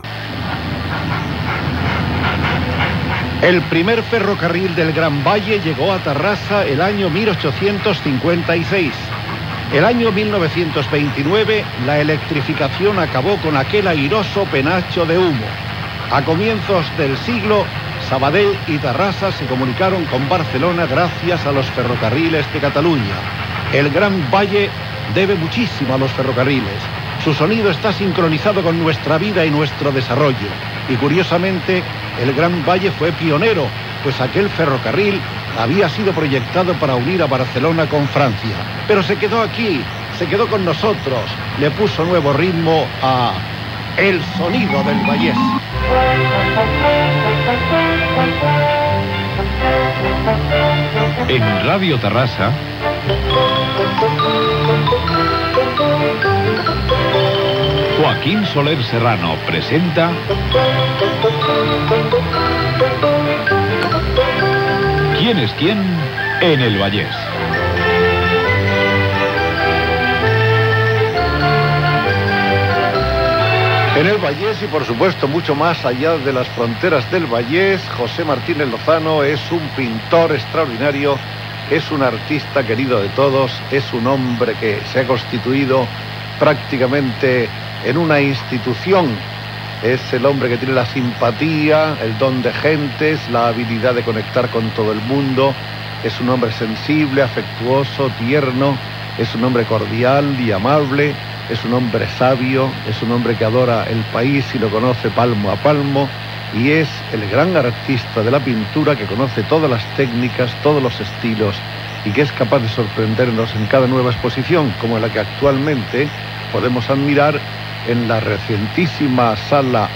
Història del tren al Vallès, careta del programa.